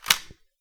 sfx_reload_0.mp3